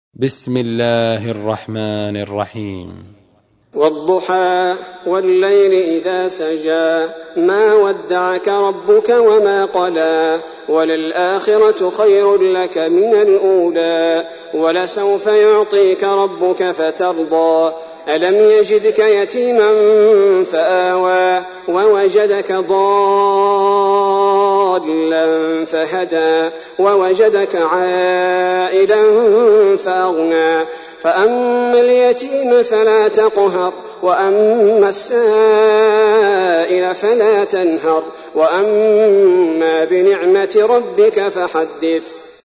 تحميل : 93. سورة الضحى / القارئ عبد البارئ الثبيتي / القرآن الكريم / موقع يا حسين